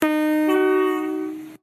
NormalHorn.ogg